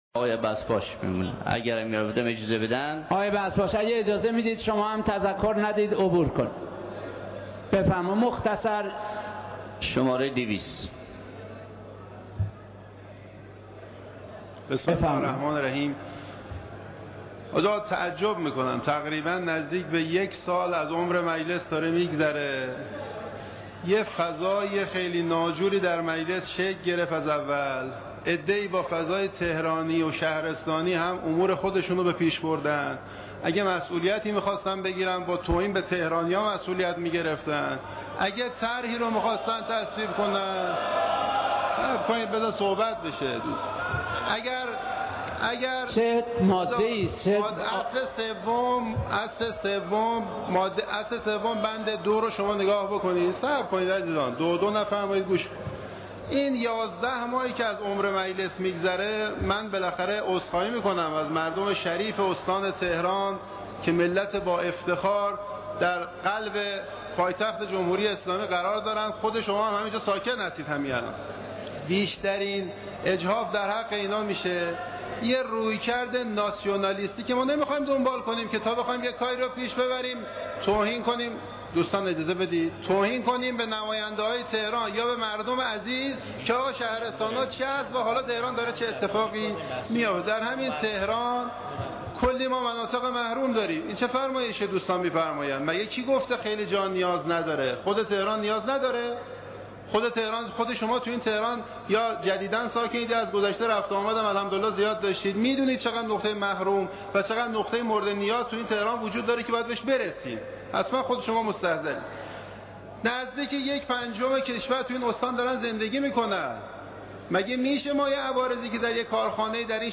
این سخنان بذرپاش با مخالفت و " دو دو " و اعتراضات نمایندگان مواجه شده که بذرپاش خطاب به آنان گفت اجازه بدهید سخنانم را مطرح کنم و  "دو دو " نکنید.